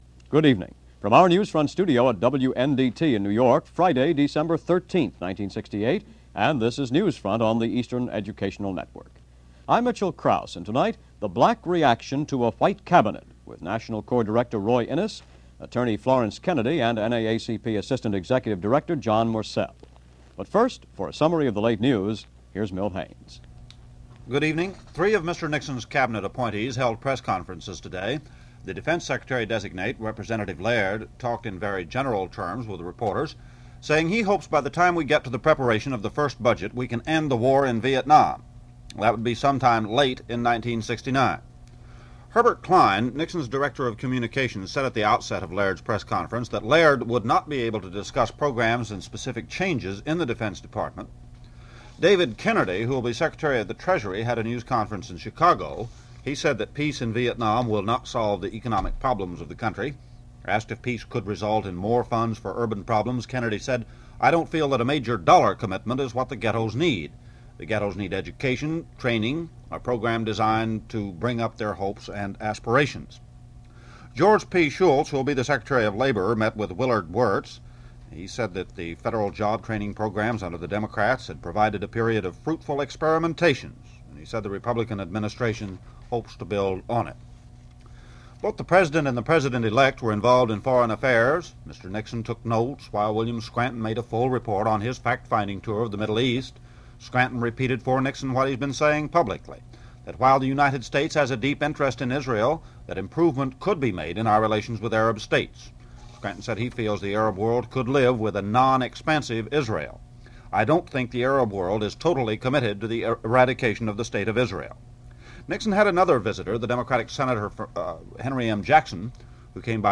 Dec. 13, 1968 - President-elect Nixon unveils his Cabinet - Activist and Lawyer Florynce Kennedy has a few words about it. News of the day.